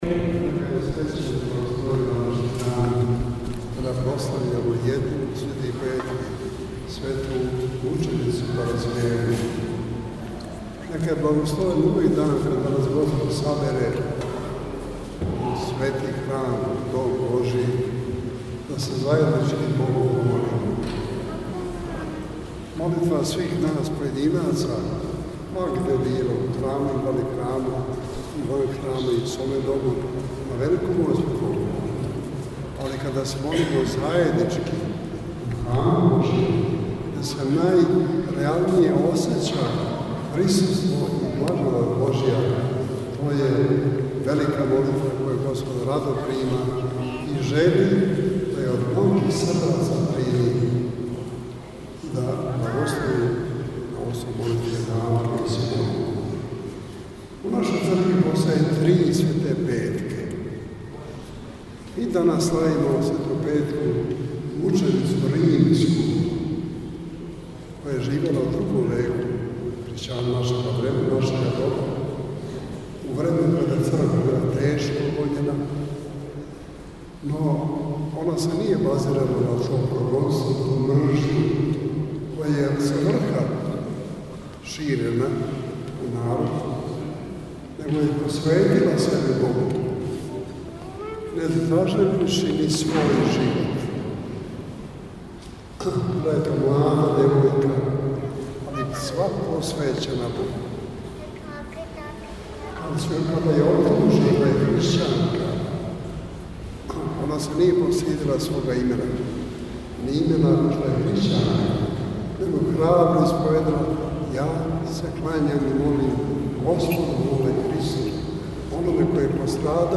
Patrijarh na Cukarickoj padini, Sv. Petka.mp3